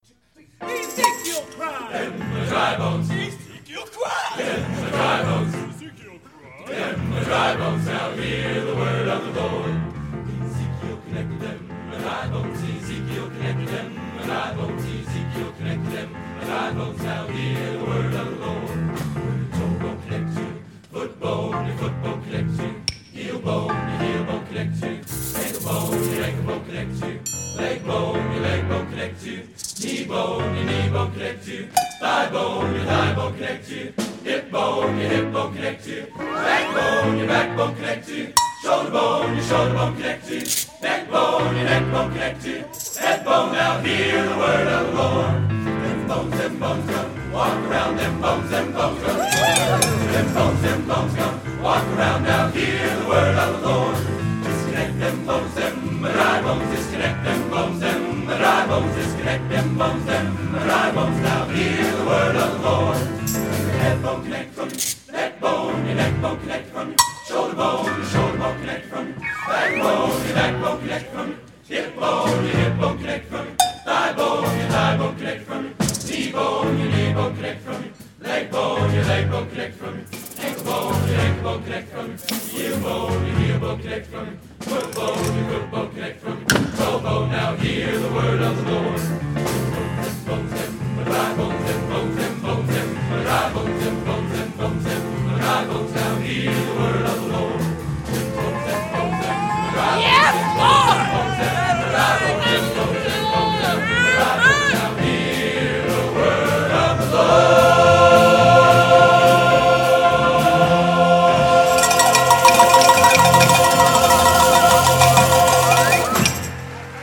Genre: Humor/Parody Spiritual | Type: